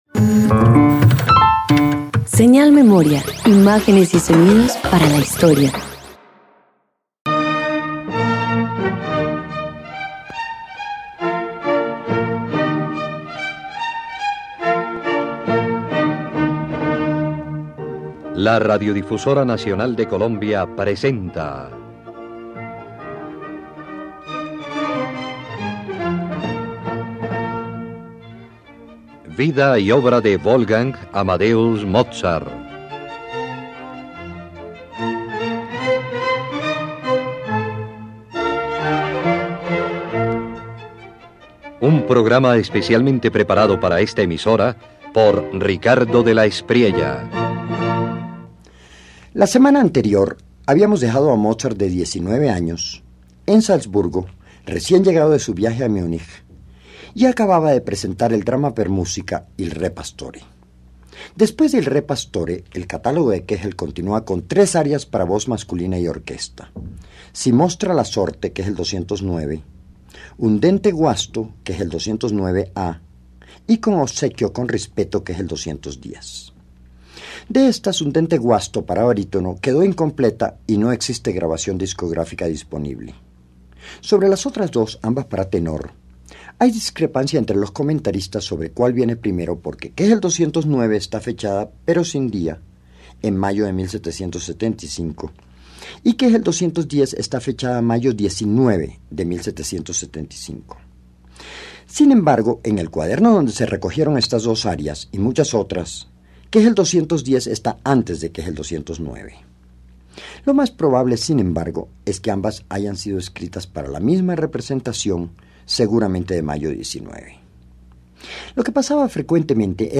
Mozart compone tres arias que revelan su versatilidad: del romanticismo delicado al humor de la ópera bufa. Así, completa el conjunto la canzoneta Ridente la calma, una pieza de dulzura serena que anticipa la sensibilidad lírica de su madurez.